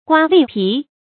刮地皮 注音： ㄍㄨㄚ ㄉㄧˋ ㄆㄧˊ 讀音讀法： 意思解釋： 比喻貪官污吏千方百計地搜刮人民的財產。